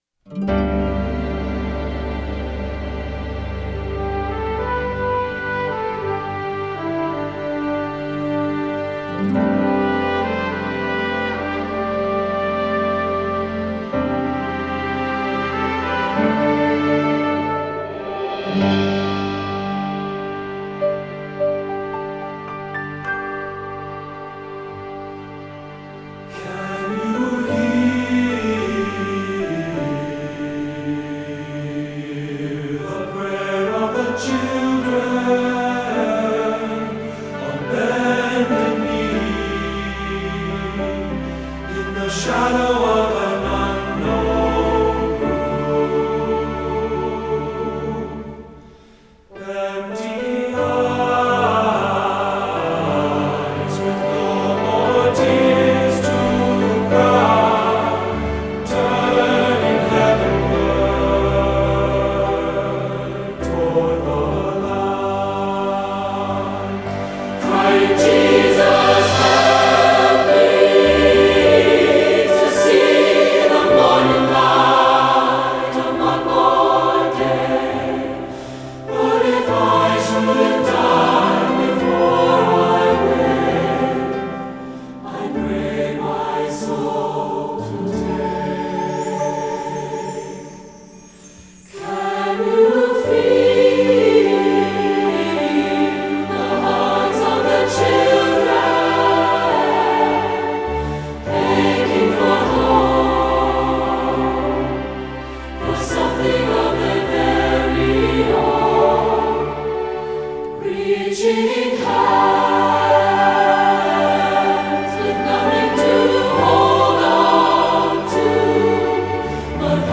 Voicing: SSAA